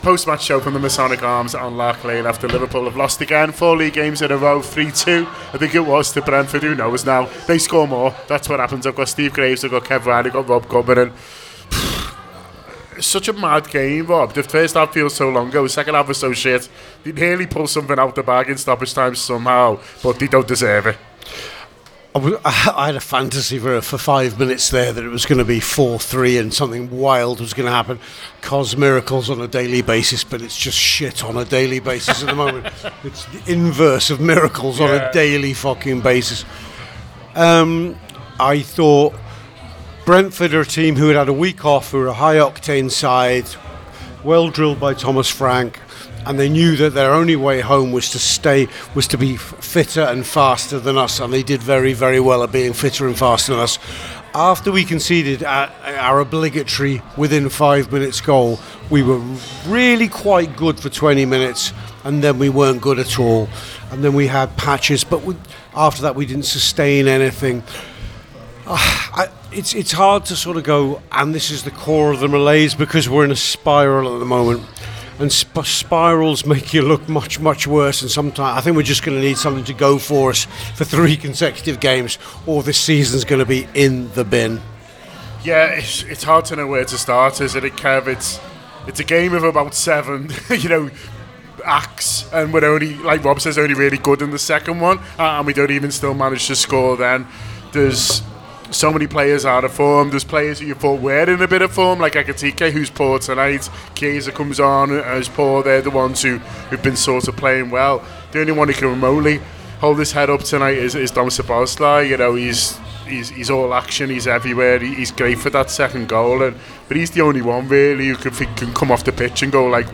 The Anfield Wrap’s post-match reaction podcast following a fourth consecutive league defeat, this time away to Brentford.